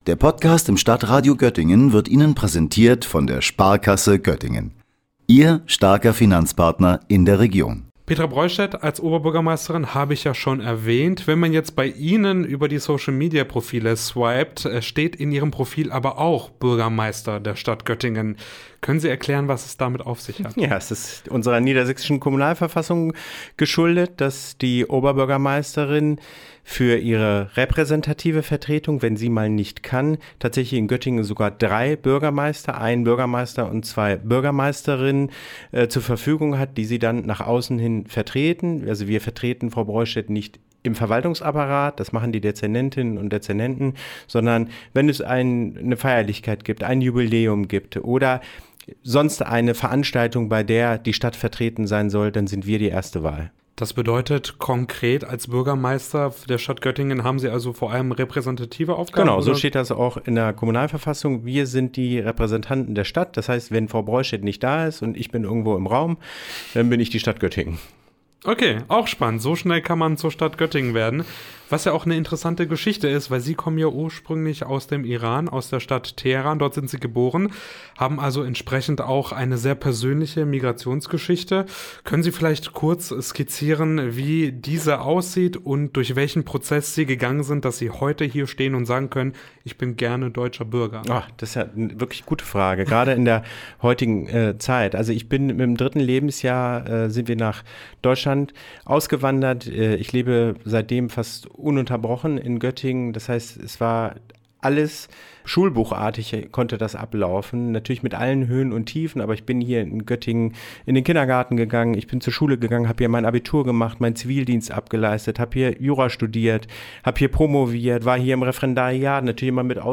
Wir sollten keine Dinge gegeneinander ausspielen – Göttingens Bürgermeister Ehsan Kangarani im Interview